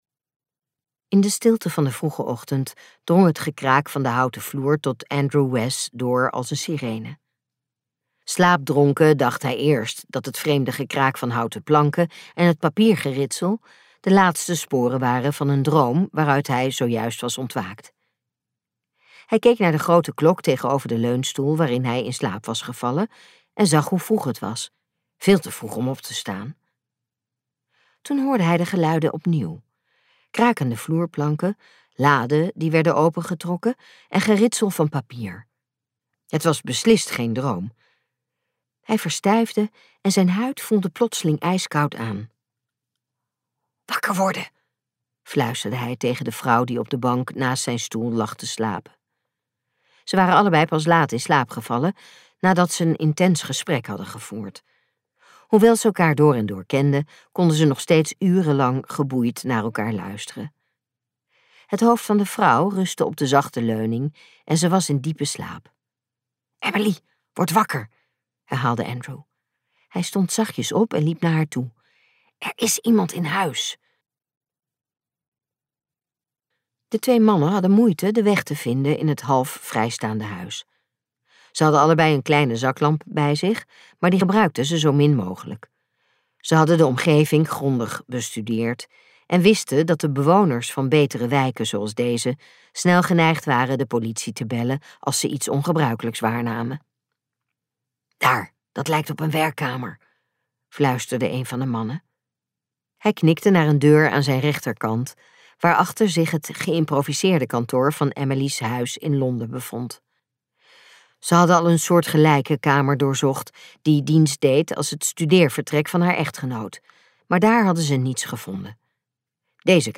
Uitgeverij De Fontein | De geheime sleutel luisterboek